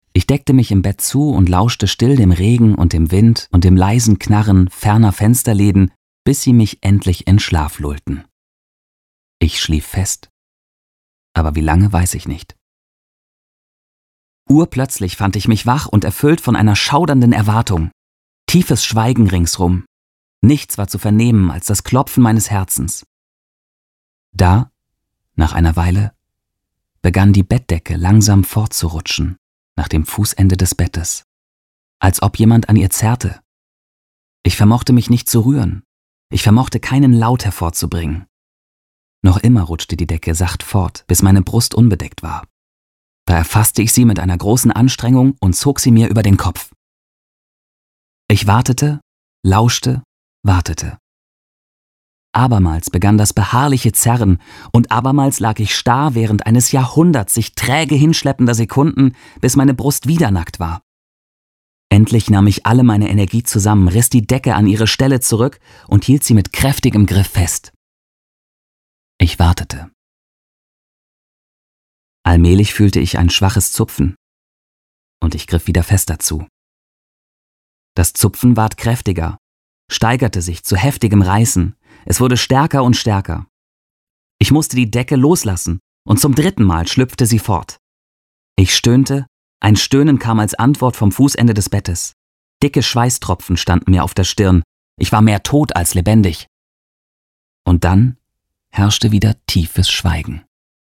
Meine Stimme: Frisch, lebendig, klar, präzise, freundlich, gefühlvoll, warm, verbindlich, seriös.
• Mikrofone: Neumann U87 Ai & TLM 103
Hörbuch
DEMO-Hoerbuch.mp3